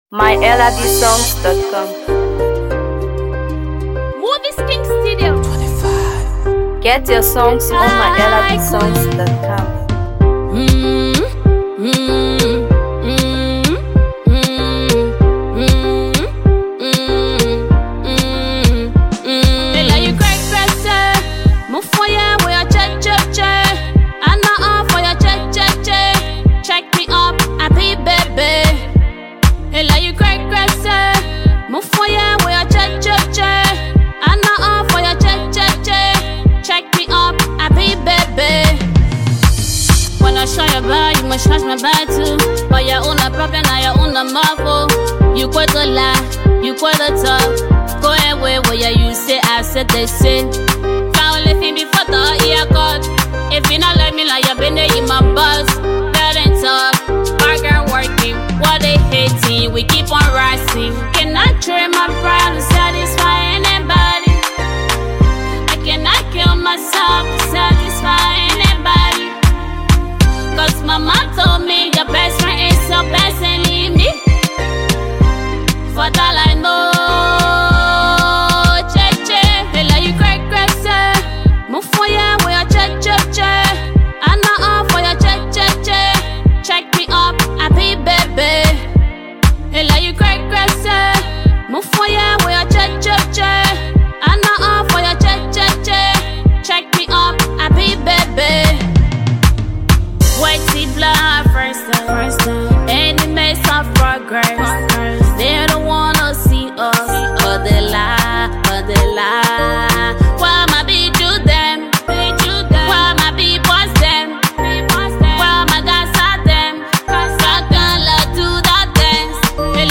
Afro PopMusic
an infectious rhythm and engaging lyrics